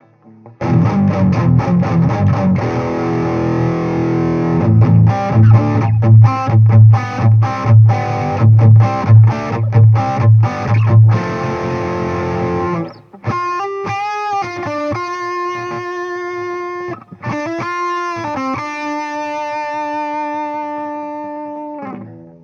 V BTE  Audio High Gain Amplifier  som stiahol Basy z 3/10 na 0,7/10 a vysky naplno, plus za konvoluciou jeden EQ s pridanymi vyskami len tak od oka.